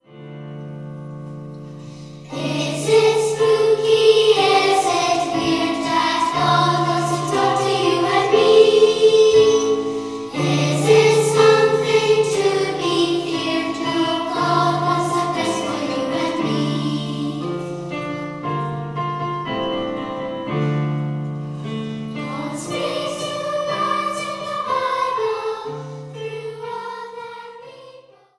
* Mp3 • Anglican Hymn: “Is it spooky?”